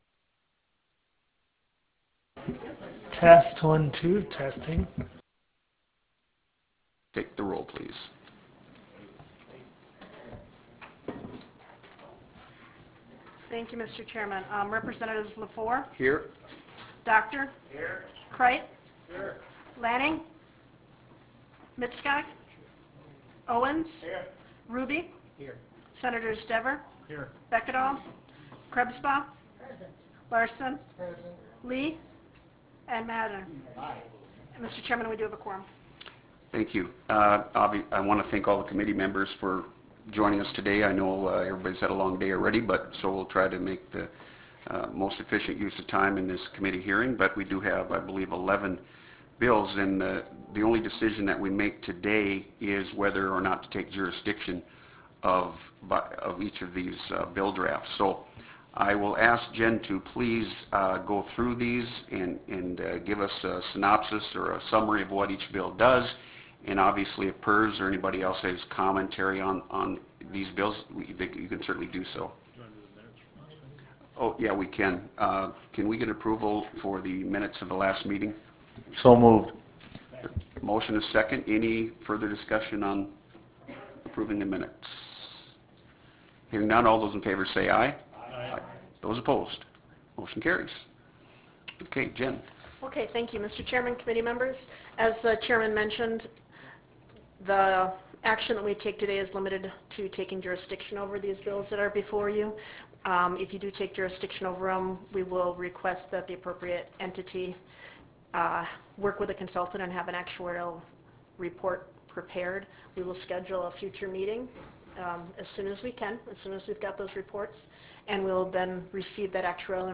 Pioneer Room State Capitol Bismarck, ND United States